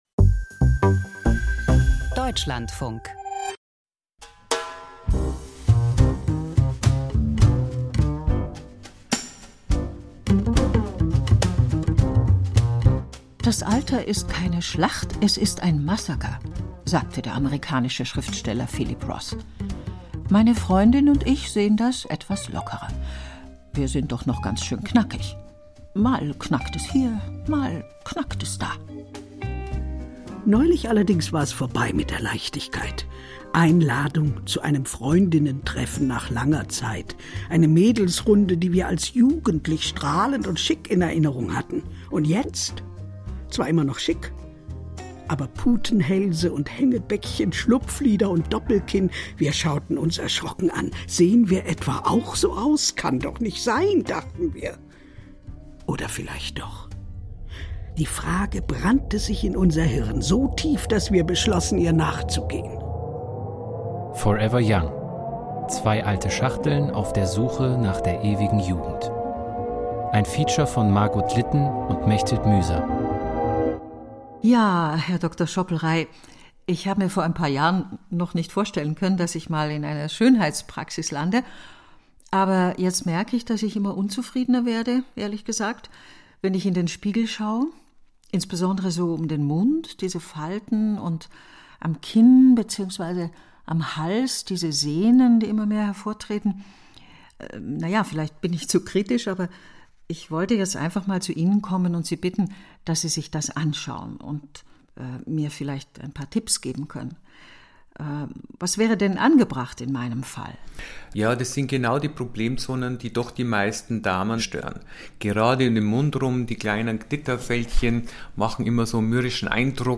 Email Audio herunterladen Fünf belarussische Journalistinnen erzählen von ihren Erfahrungen und ihrer Arbeit in einem totalitären Staat. Es ist eine universelle Geschichte: von Repression und Gewalt, von Willkür und Gefängnis und schließlich von Flucht und Exil.